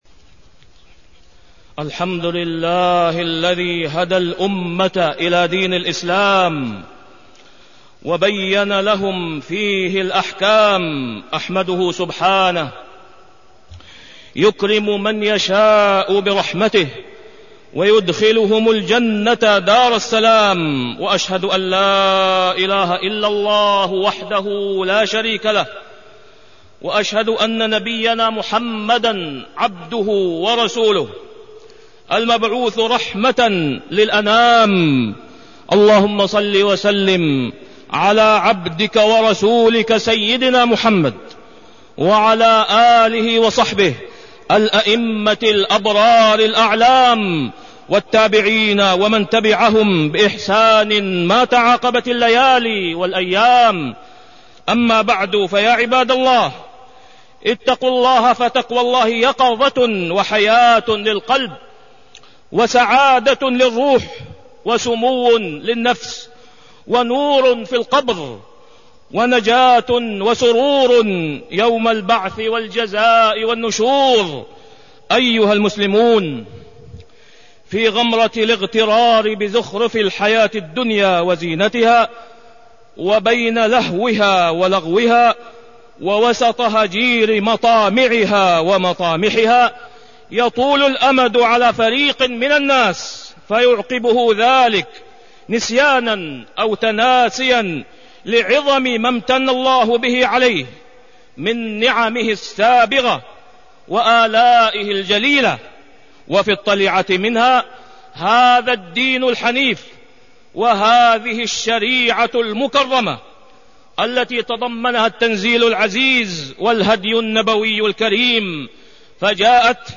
تاريخ النشر ٢ جمادى الأولى ١٤٢٣ هـ المكان: المسجد الحرام الشيخ: فضيلة الشيخ د. أسامة بن عبدالله خياط فضيلة الشيخ د. أسامة بن عبدالله خياط الحجاب والإختلاط